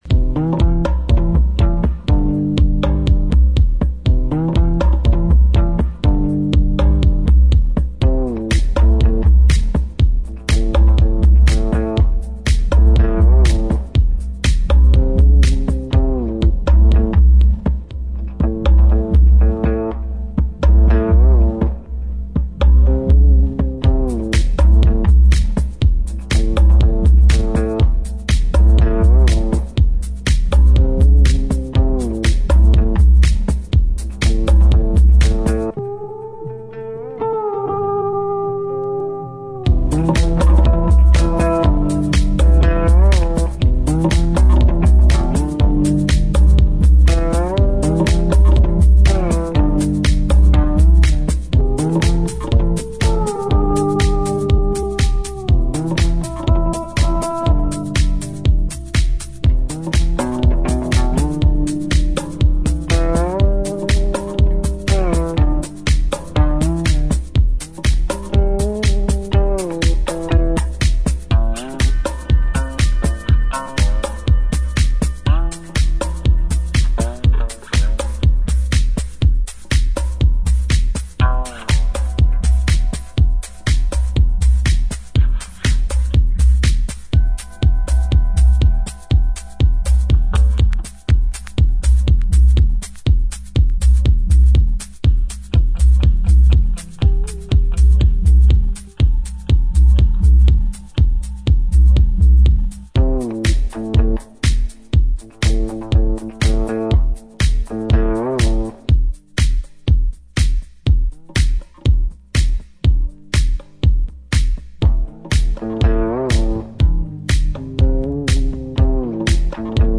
ウッド・ベース等のジャジーな要素を取り入れた渋いディープ・ハウス！